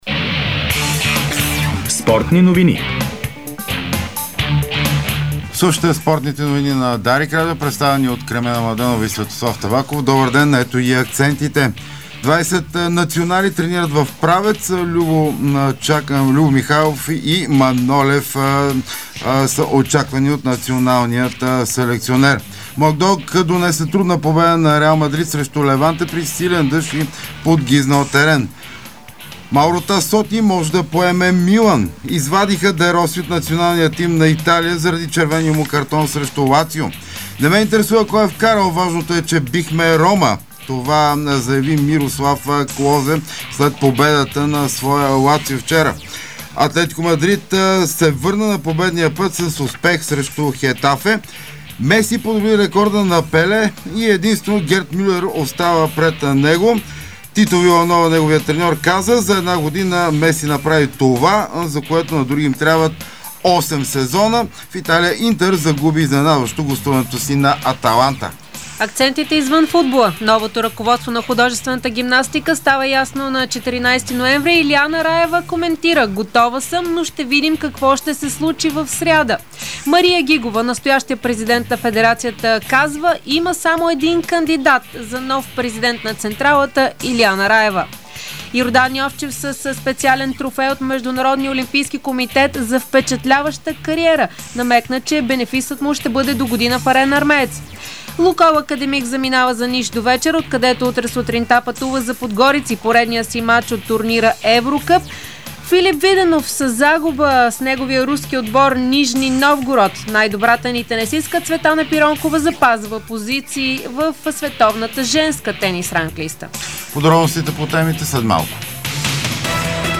Спортни новини - 12.11.2012